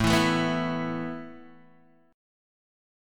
Asus2 chord